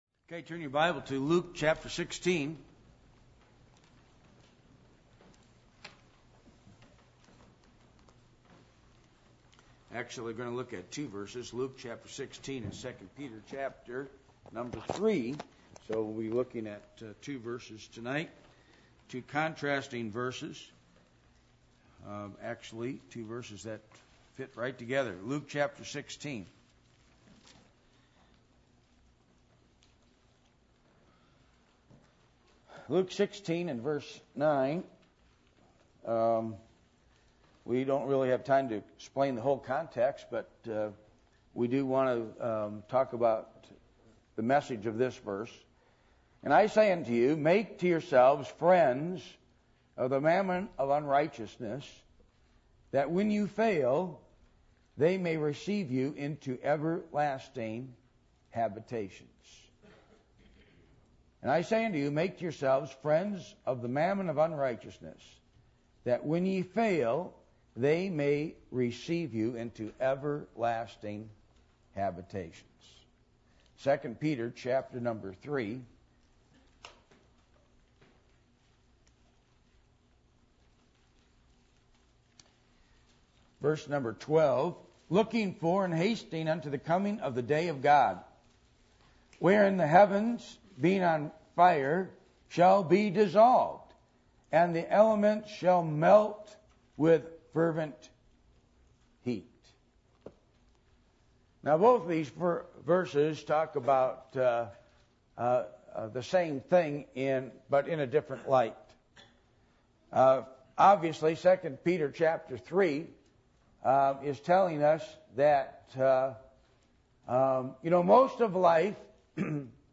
2 Peter 3:12-Luke 3:12 Service Type: Midweek Meeting %todo_render% « Reasons For Having A “Get Right” Night Keys to the Victorious Life in Christ »